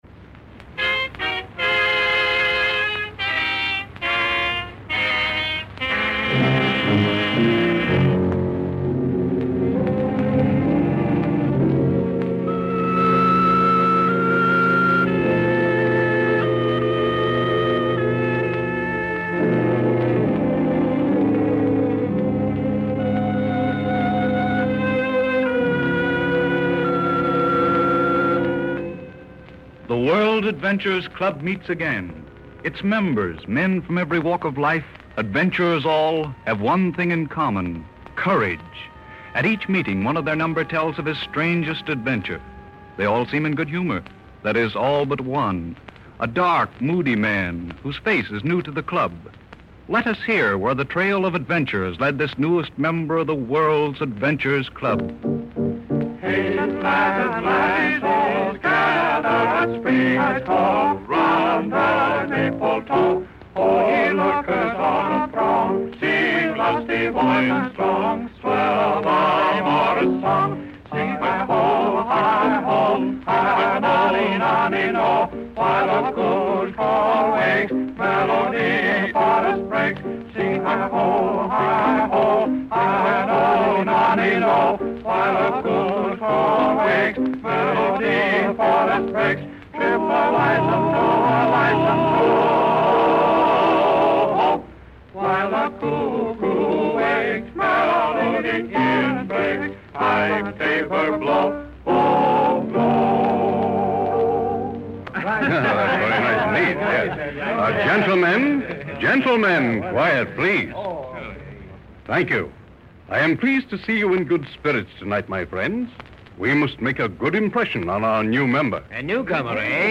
This episode is a gem from the golden age of radio, a time when storytelling was a vivid tapestry woven with words and sound effects, transporting listeners to far-off lands and thrilling escapades. The Adventurer's Club radio series, a treasure trove of tales from the 1930s, invites us into the smoky rooms of a gentleman's club where daring souls recount their extraordinary experiences.